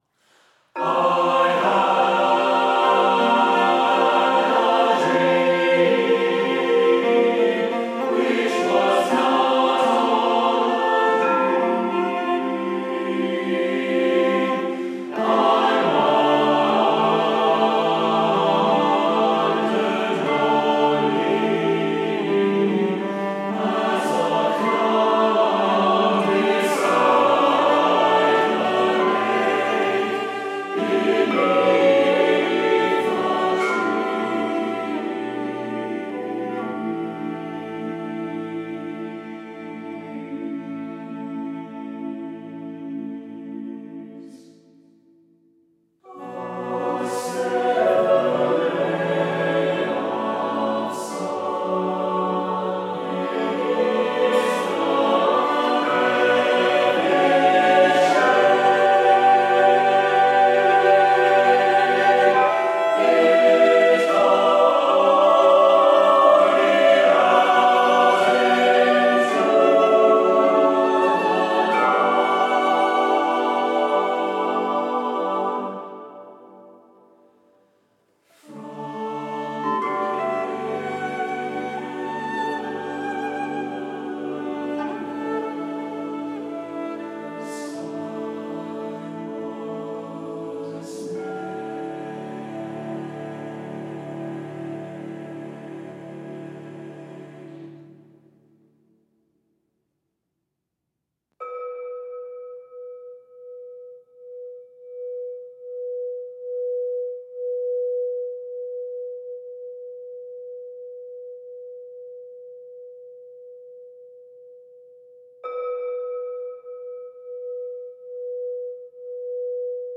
SATB Chorus with Divisi, Cello, & Crystal Singing Bowls
“Soaring and Eclectic.”